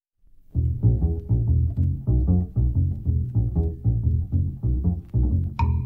Bassline - Medium Jazz.wav